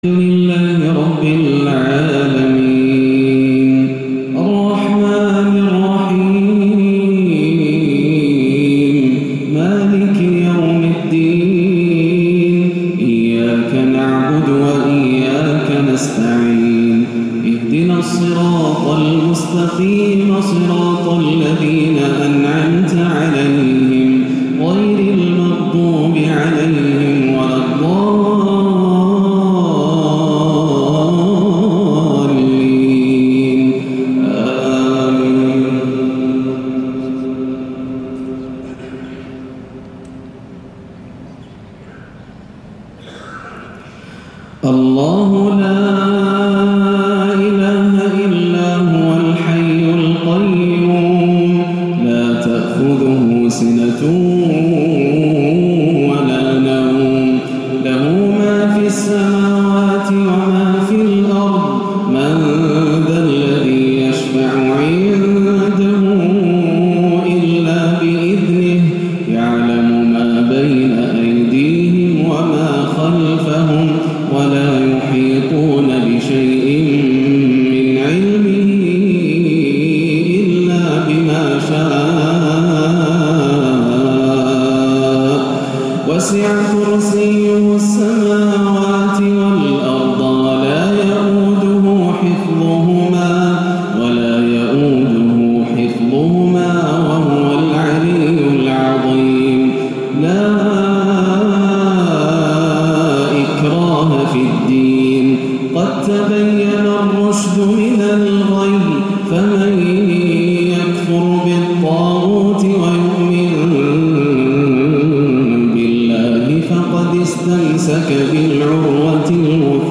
(الله ولي الذين آمنوا) من أجمل تلاوات شيخنا الفاضل تلامس القلوب وتحرك المشاعر - مغرب الجمعة 13-1 > عام 1438 > الفروض - تلاوات ياسر الدوسري